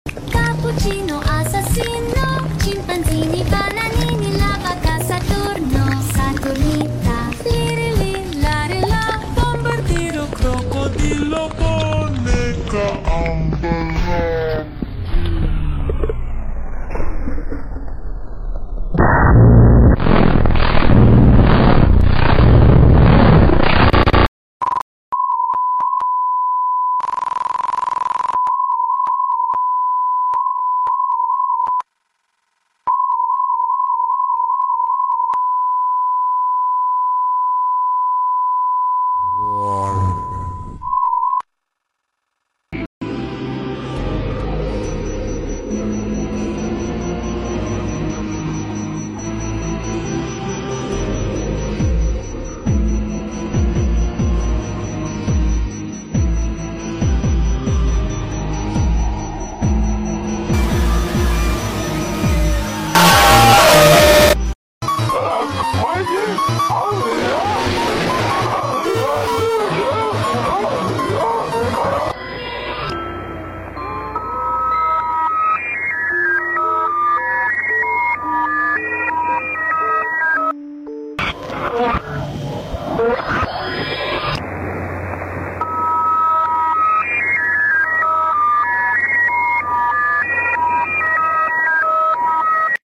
Let’s eating super lit mukbang sound effects free download
Let’s eating super lit mukbang fun asmr b